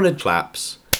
bg_100_volume10.wav